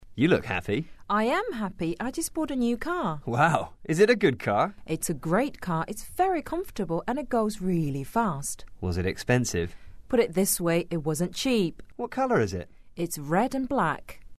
english_22_dialogue_2.mp3